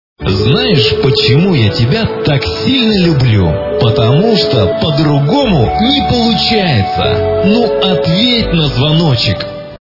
» Звуки » Люди фразы » Мужской голос - Знаешь, почему я тебя так сильно люблю?
При прослушивании Мужской голос - Знаешь, почему я тебя так сильно люблю? Потому что по-другому не получается. Ответь на звоночек! качество понижено и присутствуют гудки.
Звук Мужской голос - Знаешь, почему я тебя так сильно люблю? Потому что по-другому не получается. Ответь на звоночек!